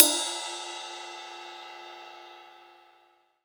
DJP_PERC_ (18).wav